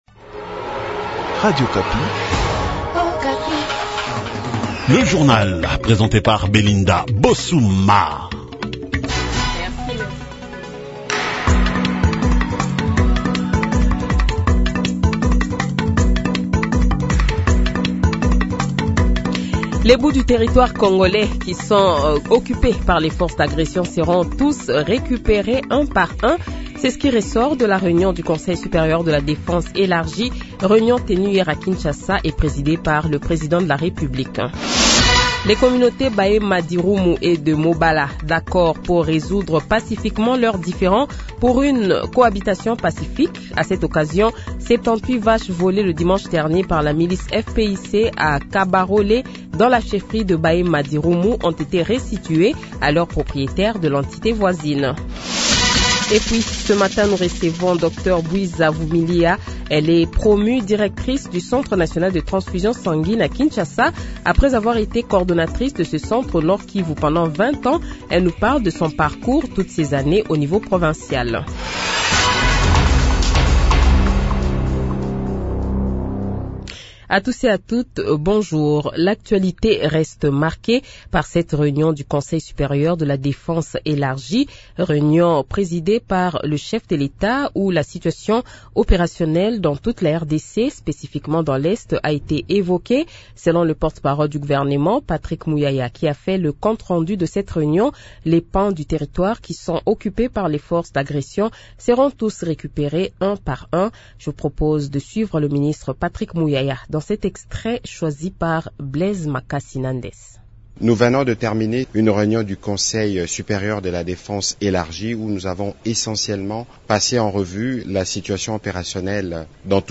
Journal Francais Matin
Le Journal de 8h, 09 Janvier 2025 :